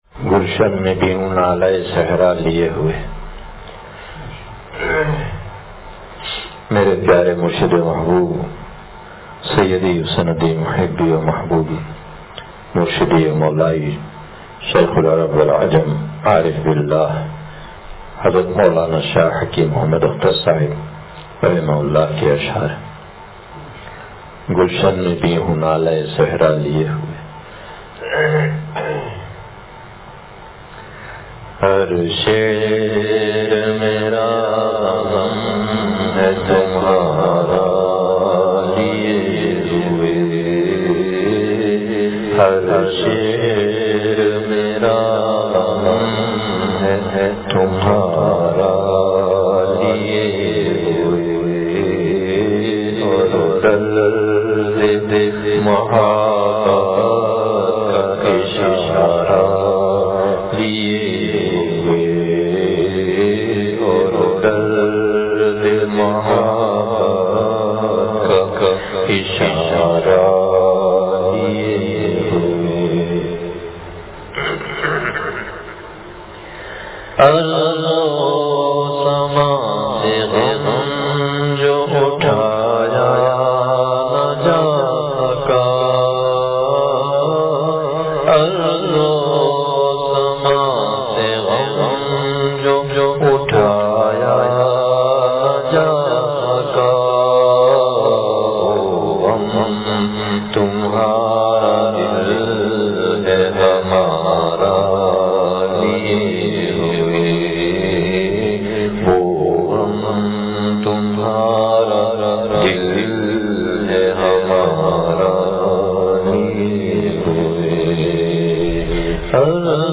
گلشن میں بھی ہوں نالۂ صحرا لئے ہوئے – بدھ بیان – دنیا کی حقیقت – نشر الطیب فی ذکر النبی الحبیب صلی اللہ علیہ وسلم